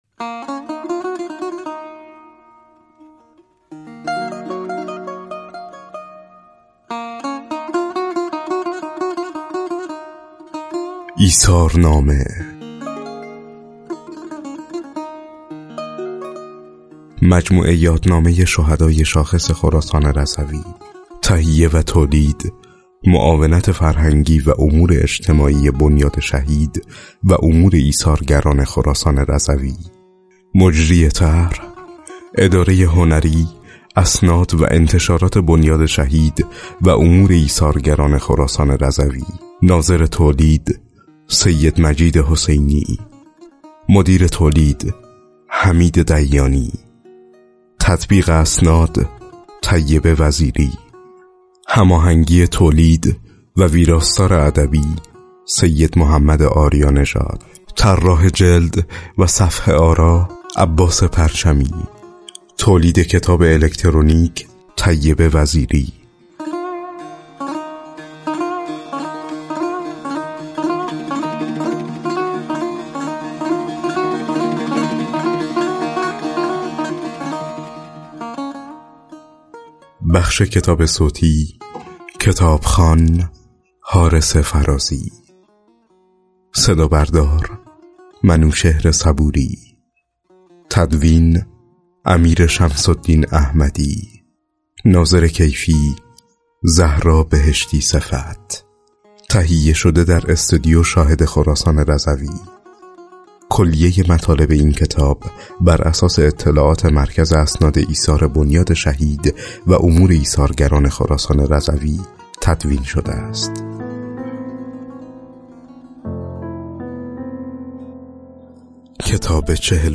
بر این اساس کتاب‌های جیبی، الکترونیکی، و کتاب صوتی 72 تن از شهیدان شاخص استان از میان شهیدان انقلاب اسلامی، ترور، دفاع مقدس، مرزبانی، دیپلمات و مدافع حرم منتشر و رونمایی شده است.